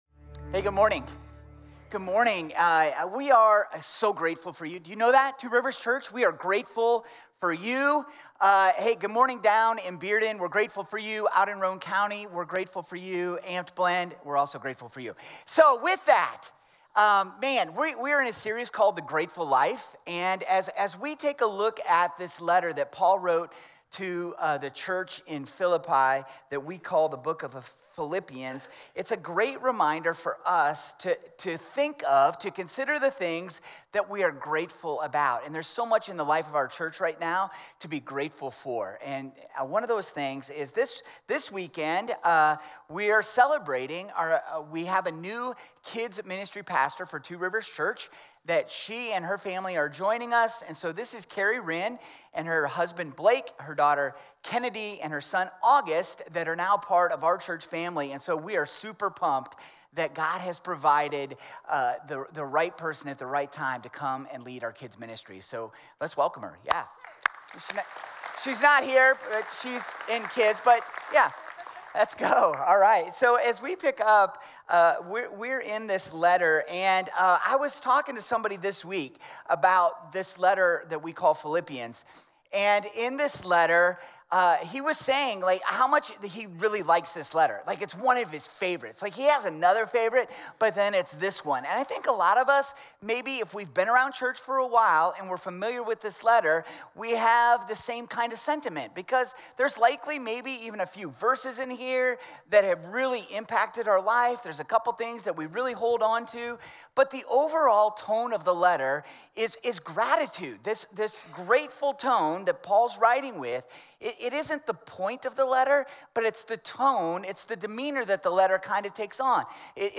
Two Rivers Church Sermons « » The Grateful Life